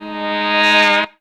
SHORT SWL 1.wav